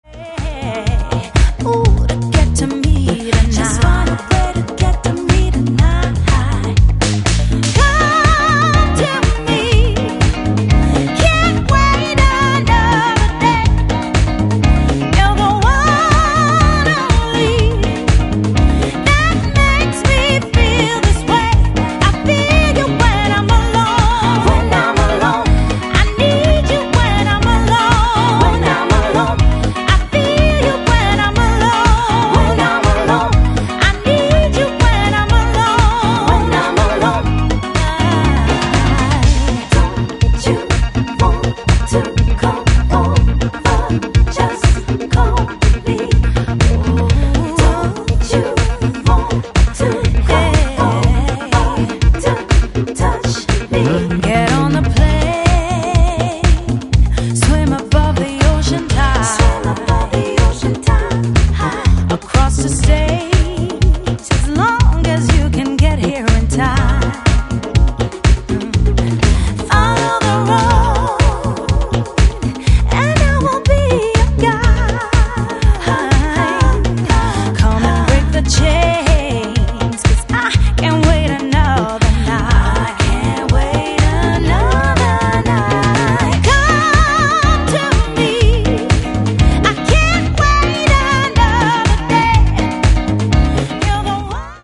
ジャンル(スタイル) NU DISCO / DISCO / HOUSE / RE-EDIT